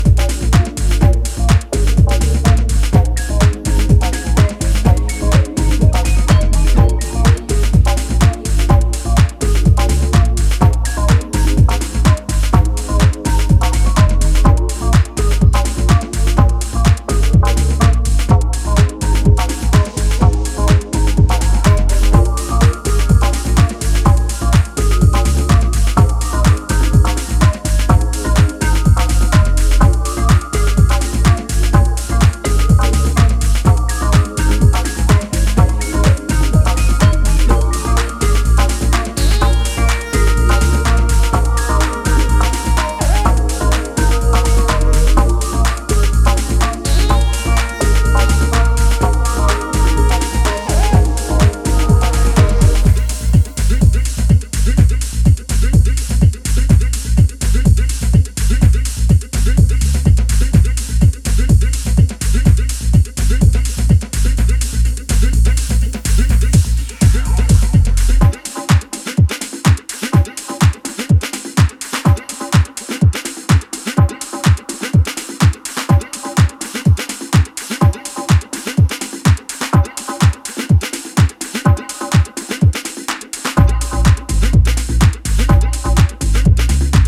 Ready for another round of lush, deep and unique sounds